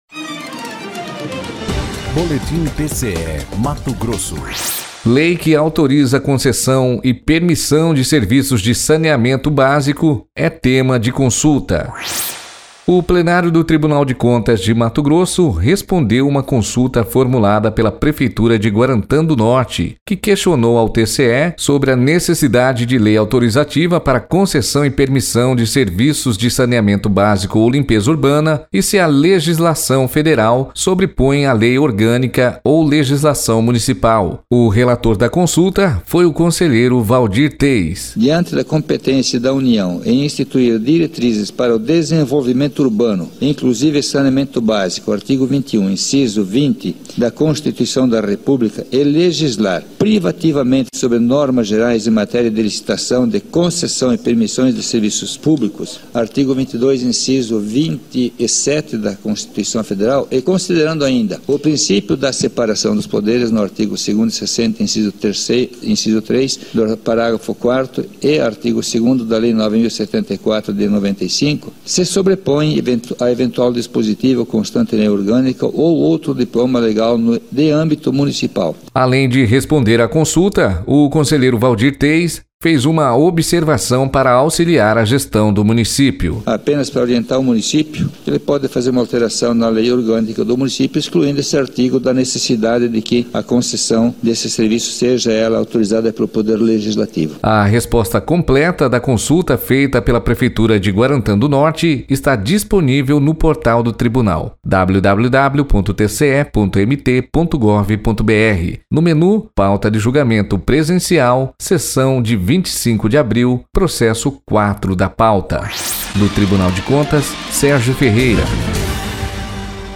Sonora: Waldir Teis – conselheiro do TCE-MT